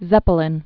(zĕpə-lĭn, zĕplĭn, tsĕpə-lēn), Count Ferdinand von 1838-1917.